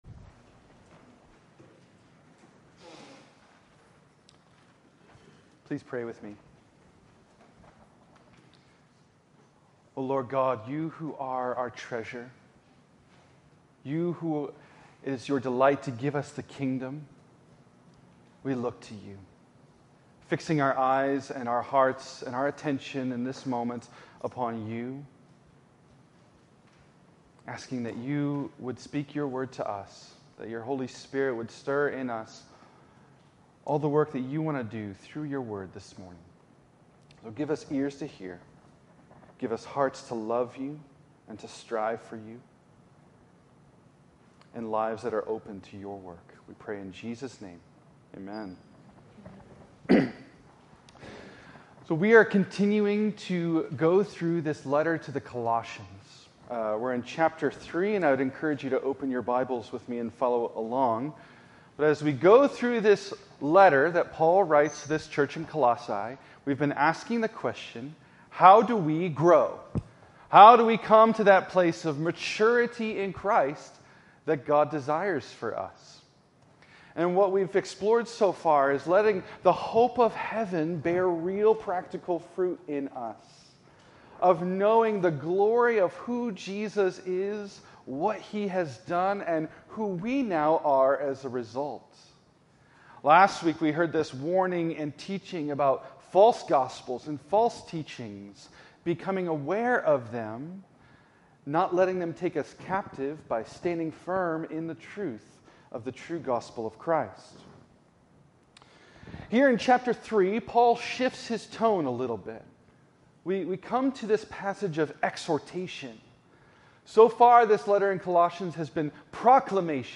Colossians 3:1-17 Series: Colossians: Growth in Christ Tagged with Colossians , Exhortation , Gospel , ordinary time , spiritual growth